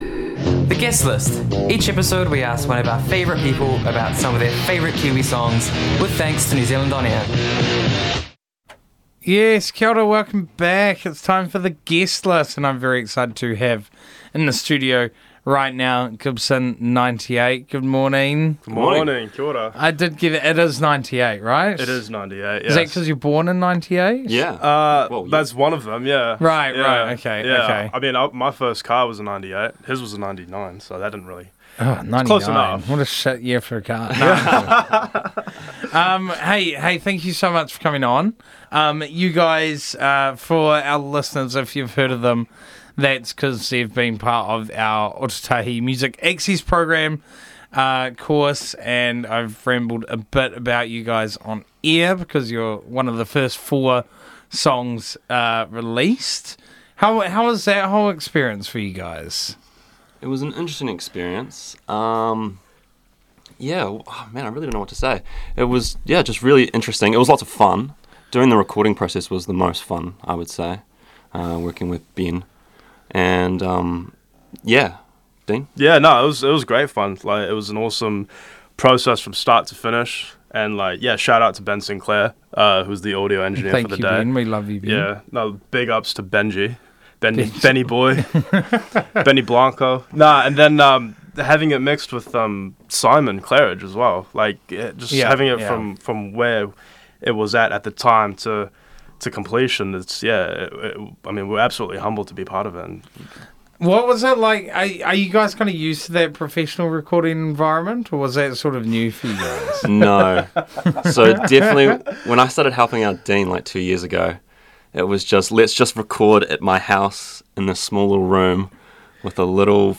ŌMAP recipients, and local musical duo, Gibson XCVIII, joined Burnt Breakfast in the studio for this week’s Guest List. The pair talk about their choices, how it’s inspired them, how their creative process works, and what’s up next for them.